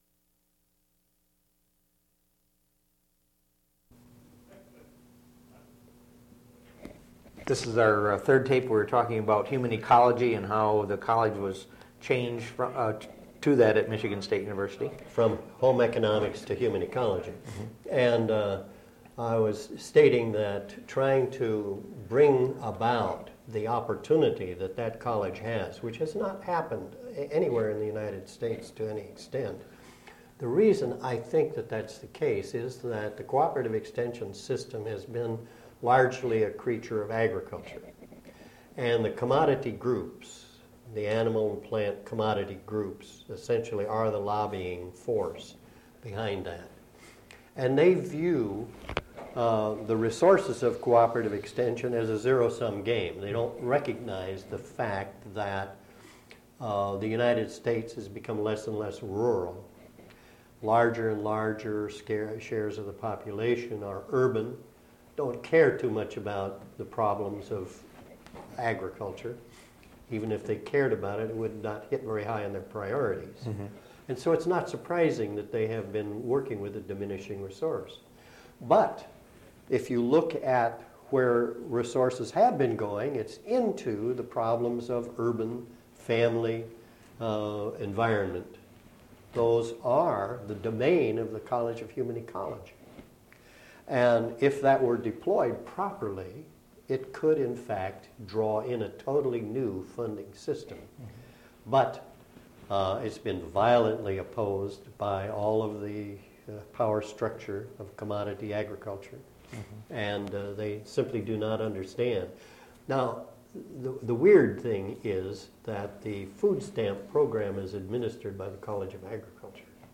Interview
Original Format: Audiocassettes